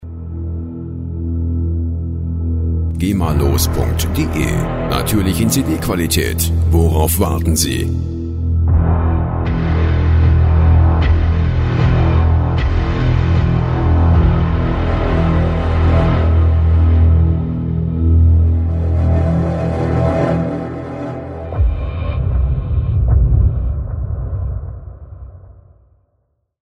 rechtefreie Audio Logos
Musikstil: Trailer Music
Tempo: 77 bpm